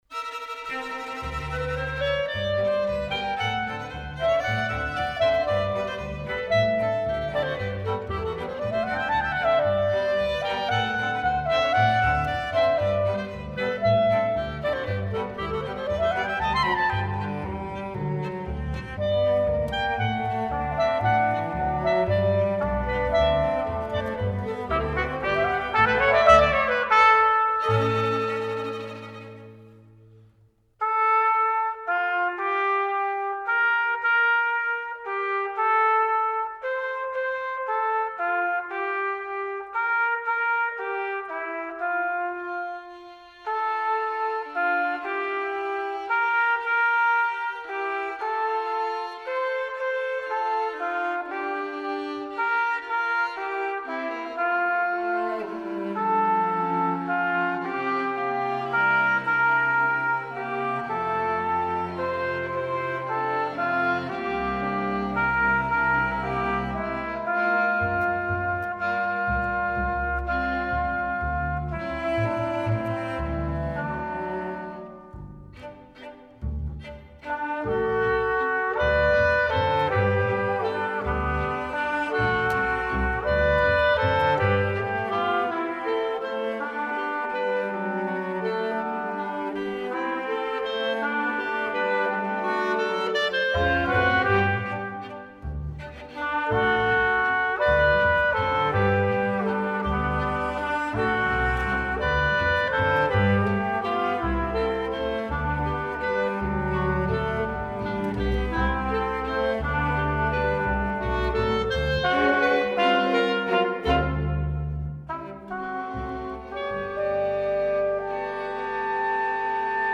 The music resulted in a small suite in four movements, one for each of the main parts of the play.
Violin
Trumpet
Clarinet
Cello
Contrabass